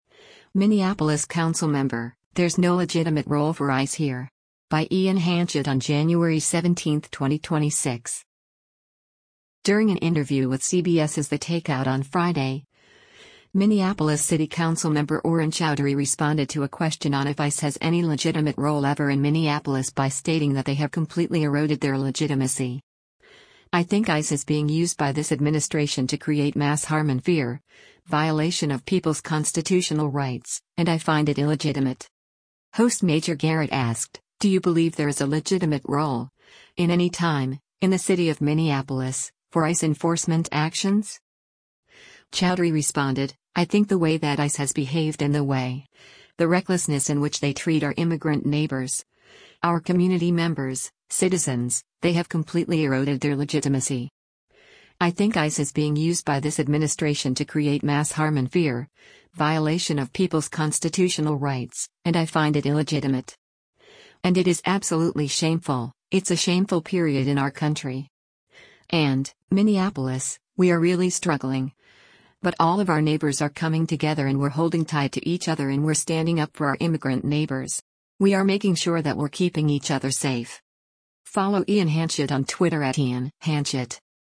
During an interview with CBS’s “The Takeout” on Friday, Minneapolis City Council Member Aurin Chowdhury responded to a question on if ICE has any legitimate role ever in Minneapolis by stating that “they have completely eroded their legitimacy. I think ICE is being used by this administration to create mass harm and fear, violation of people’s constitutional rights, and I find it illegitimate.”